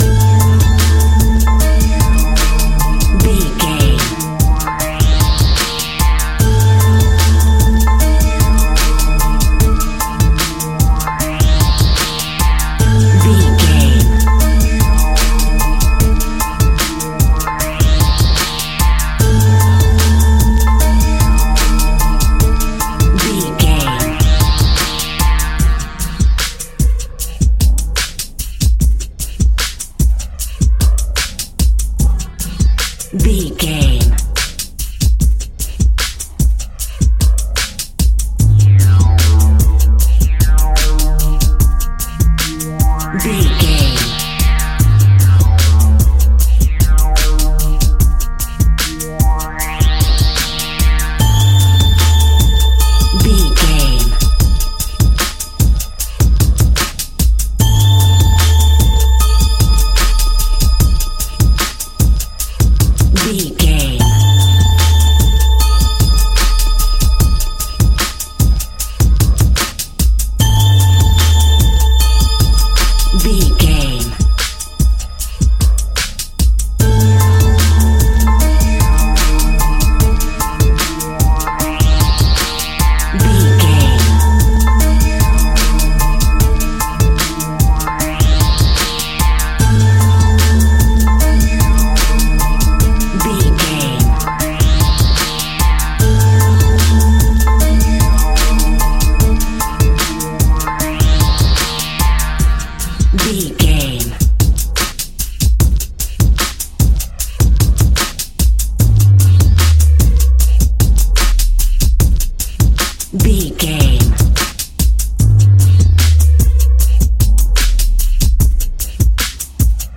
Backing Beat for Hip Hop.
Lydian
hip hop instrumentals
funky
groovy
east coast hip hop
electronic drums
synth lead
synth bass